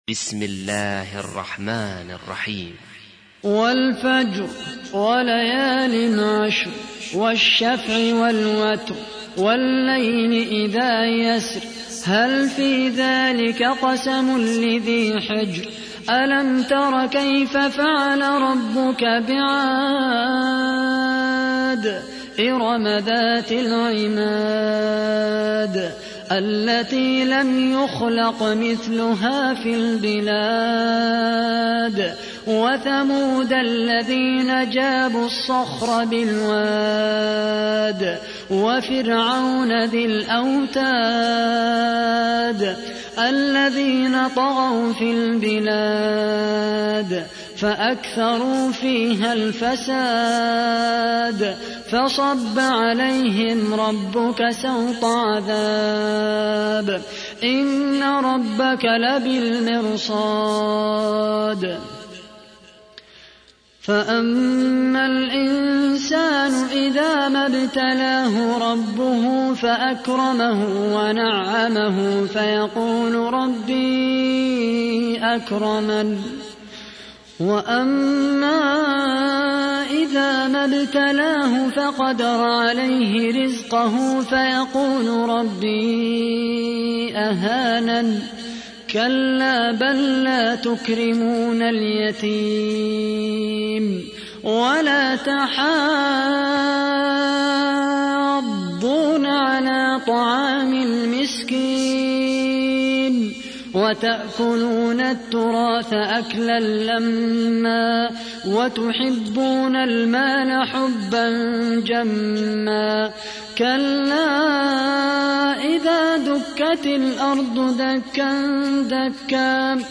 تحميل : 89. سورة الفجر / القارئ خالد القحطاني / القرآن الكريم / موقع يا حسين